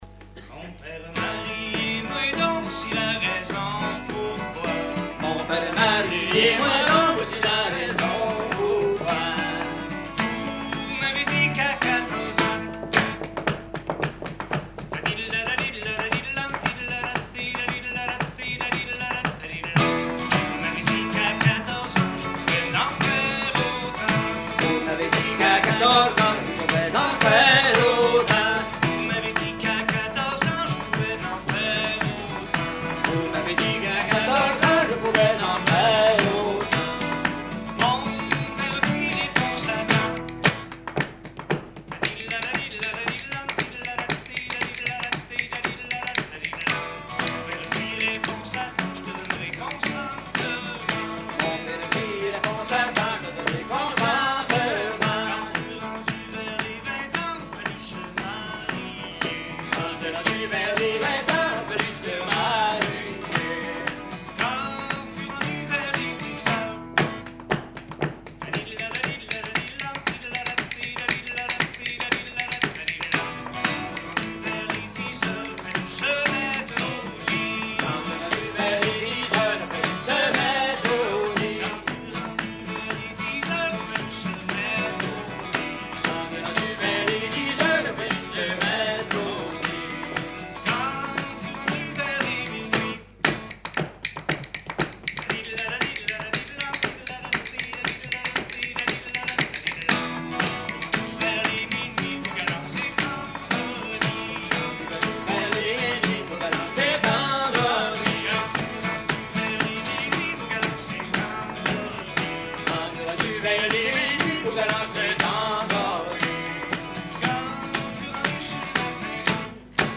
Genre strophique
Concert à la ferme du Vasais
Pièce musicale inédite